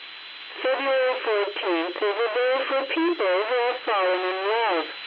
03_radio.wav